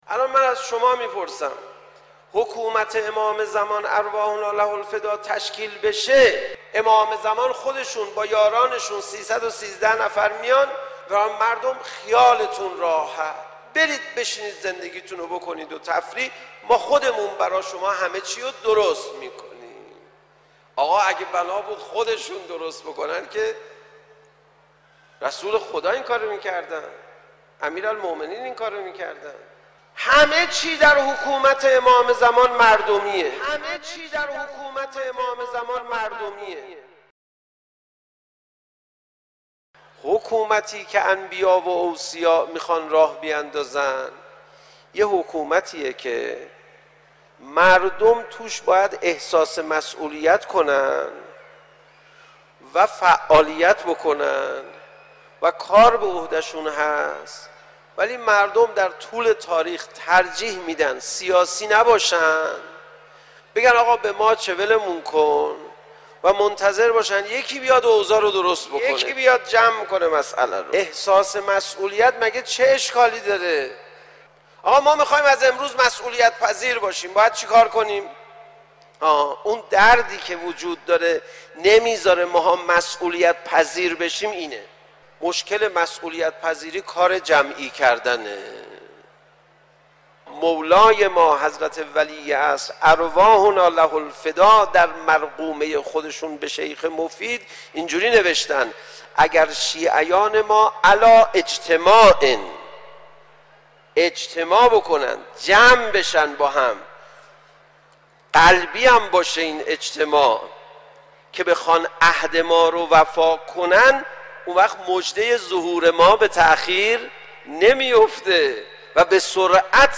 منبع : مشهدالرضا(ع) - رواق امام خمینی(ره)